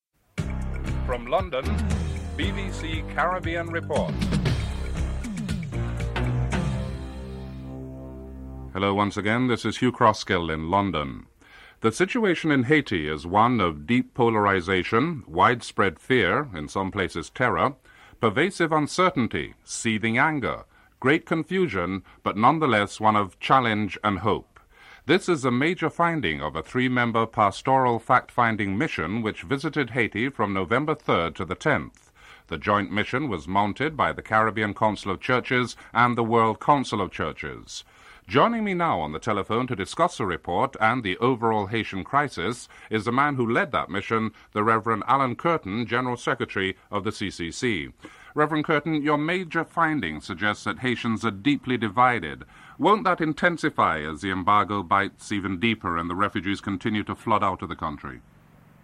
Special Report on the Haitian refugee crisis. Report ends abruptly.